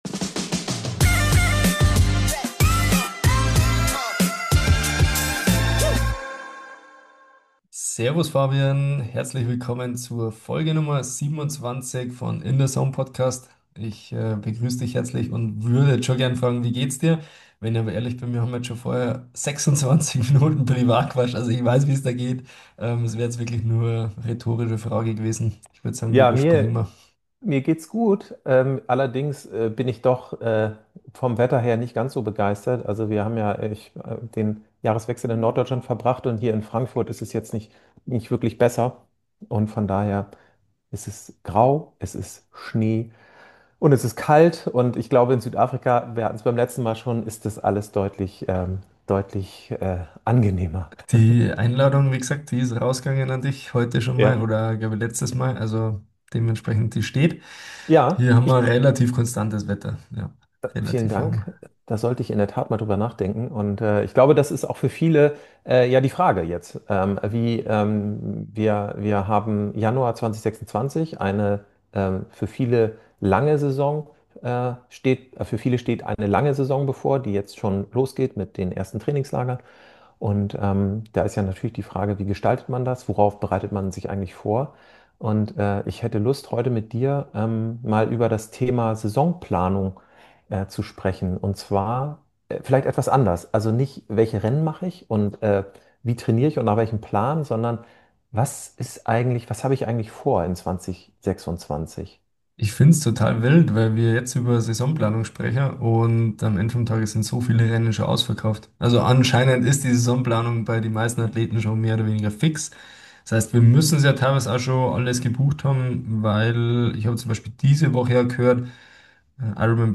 Die Folge wird an einigen Stellen bewusst etwas ruhiger und tiefer. Es geht um Mindset, Erwartungen und darum, den eigenen Gedanken einfach mal Raum zu geben.